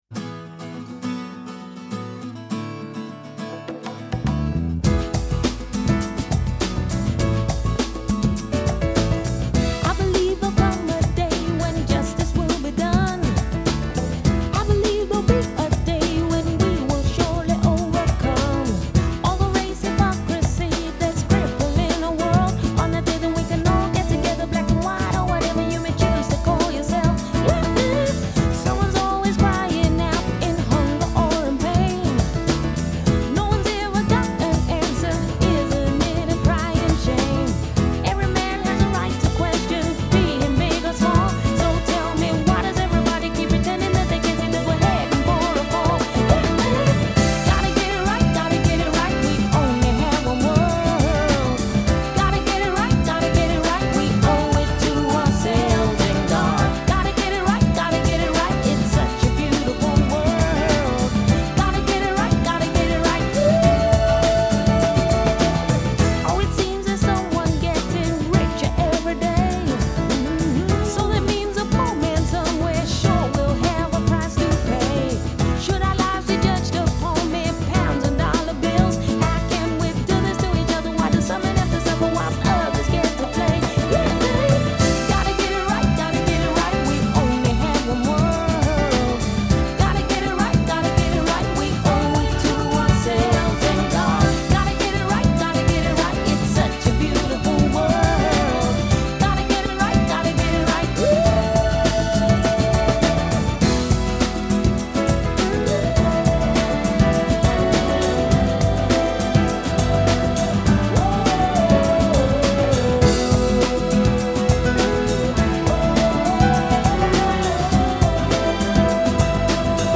soulful pop record
unclassifiable and thoroughly infectious